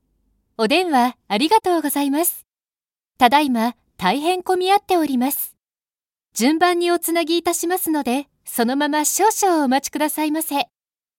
声の達人女性ナレーター
ボイスサンプル4（落ち着いた・電話アナウンス）[↓DOWNLOAD]